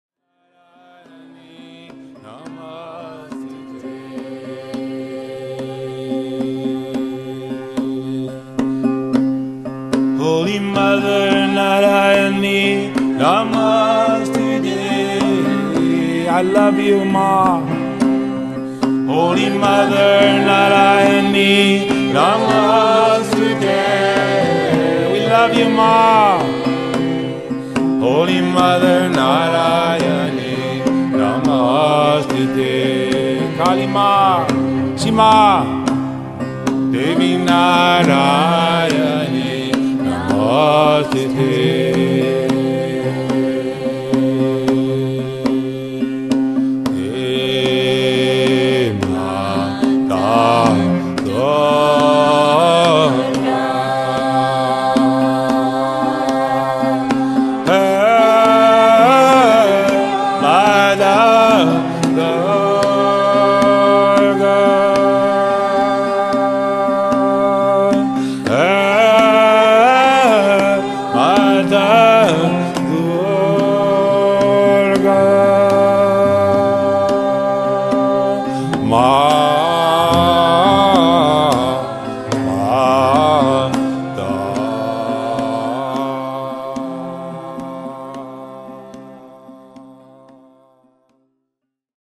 Live Kirtan Chanting CD